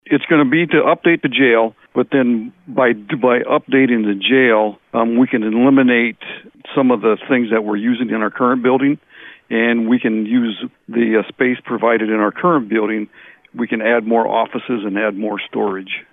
He tells us what they have planned.